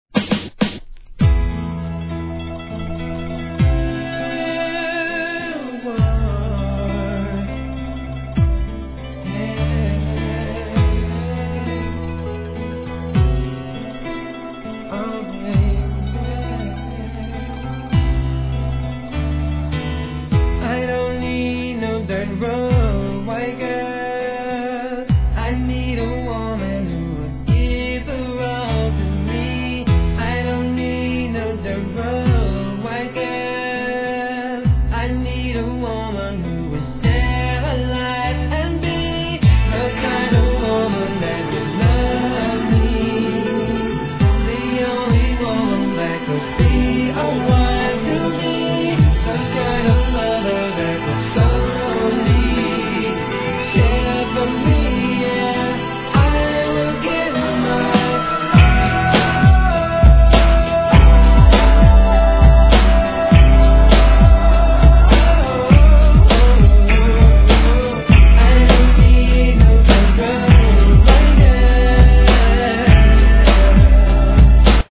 HIP HOP/R&B
本作はMIDテンポで透き通るようなヴォーカル＆コーラスの哀愁漂う逸品!